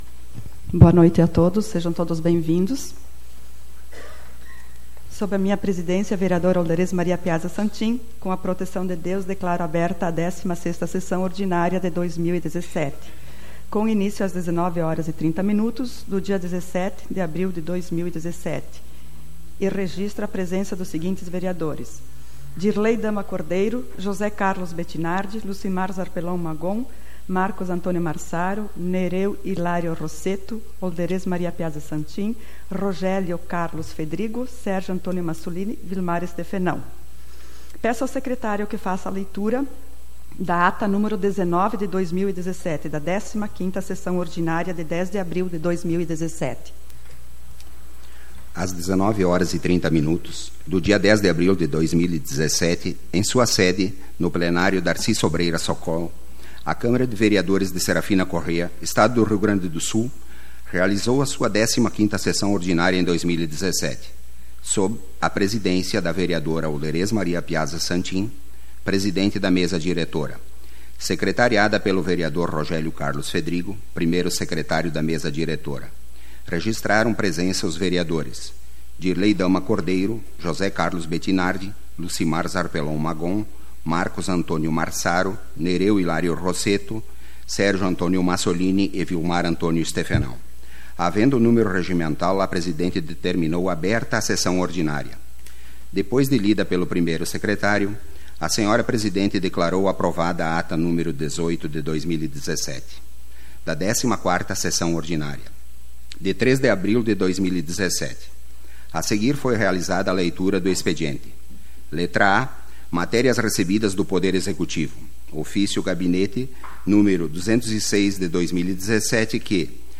Leitura da Ata nº 19/2017, da 15ª Sessão Ordinária, de 10 de abril de 2017.